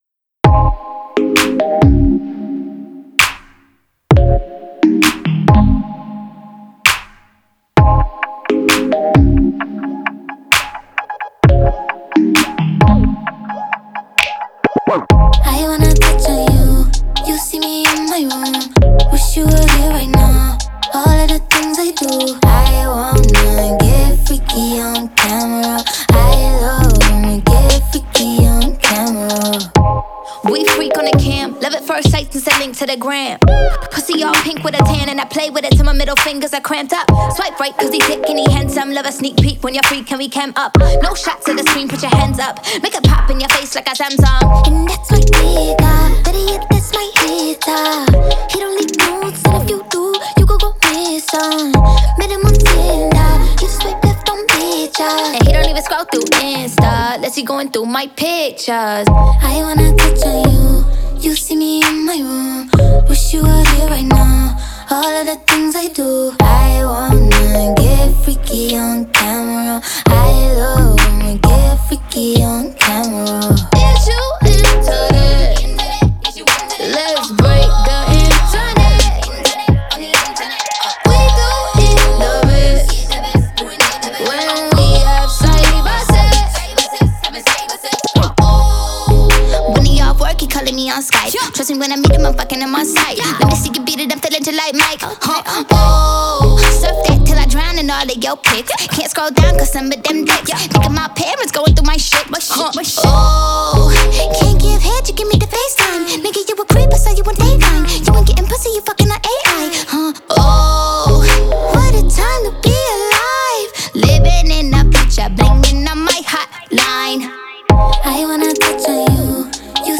سبک هیپ هاپ